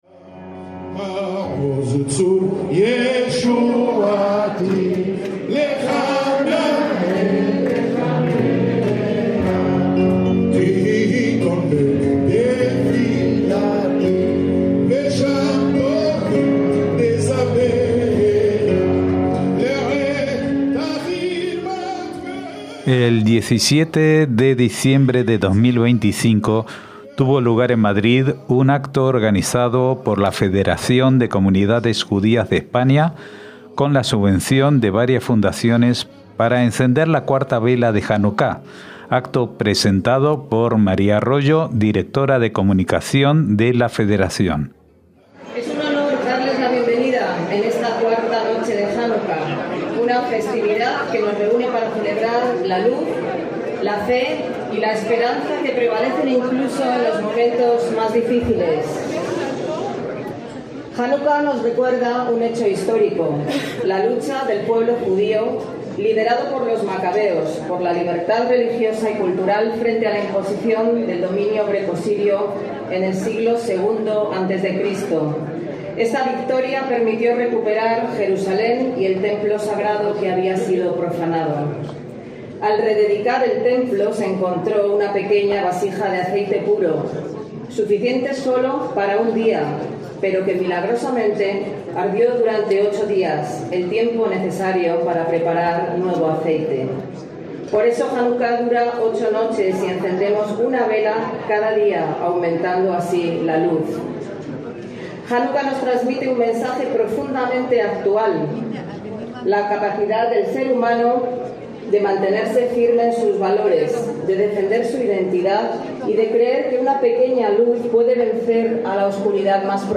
Acto de encendido de la cuarta vela de Janucá (Madrid, 17/12/2025)
NUESTRAS COMUNIDADES - El 17 de diciembre de 2025 tuvo lugar en Madrid un acto organizado por la Federación de Comunidades Judías de España con la subvención de varias fundaciones para encender la cuarta vela de Janucá. El acto contó con la destacada presencia del Gran Rabino de Jerusalén Rishon Letzion Shlomo Amar, así como diferentes personalidades.